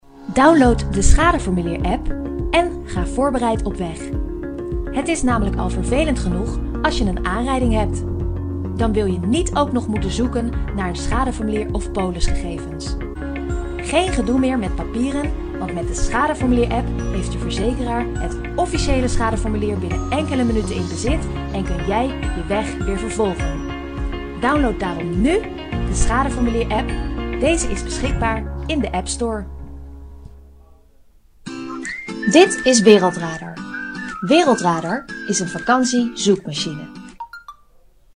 荷兰语中年大气浑厚磁性 、沉稳 、娓娓道来 、积极向上 、亲切甜美 、素人 、女专题片 、宣传片 、纪录片 、广告 、飞碟说/MG 、课件PPT 、工程介绍 、绘本故事 、动漫动画游戏影视 、200元/百单词女荷04 荷兰语女声 干音 大气浑厚磁性|沉稳|娓娓道来|积极向上|亲切甜美|素人
女荷04 荷兰语女声 温婉成熟 大气浑厚磁性|沉稳|娓娓道来|积极向上|亲切甜美|素人